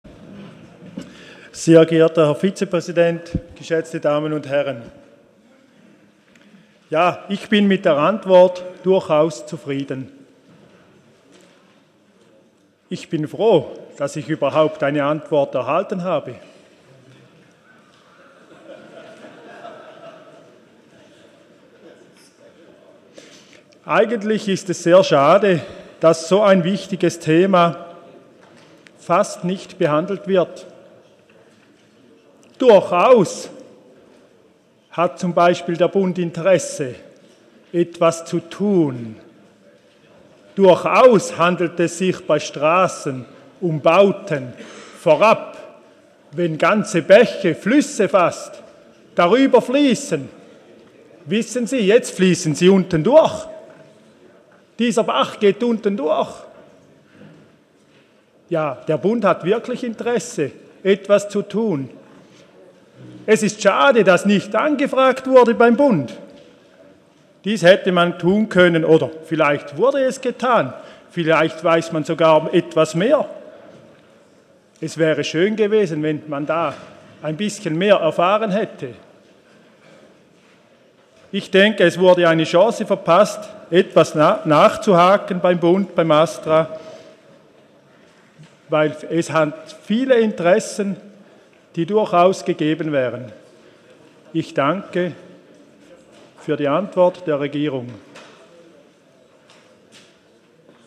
19.2.2020Wortmeldung
Session des Kantonsrates vom 17. bis 19. Februar 2020